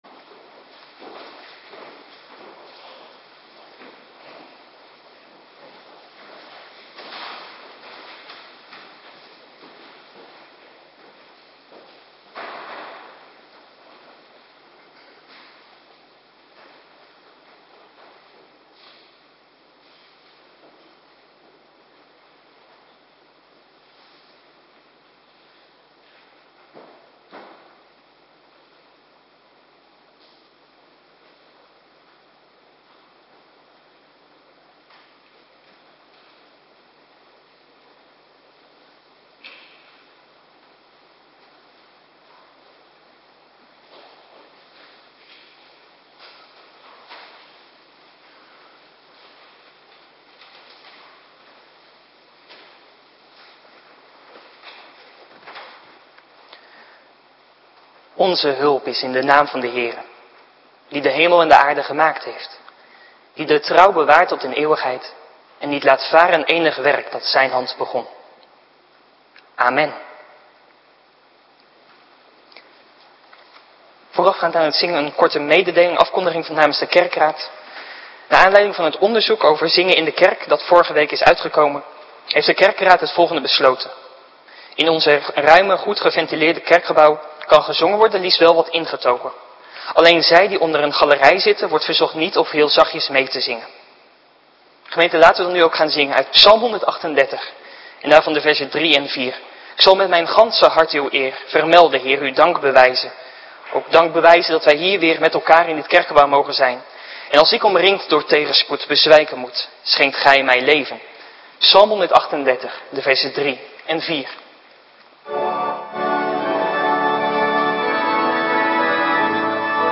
Avonddienst - Cluster 2
Locatie: Hervormde Gemeente Waarder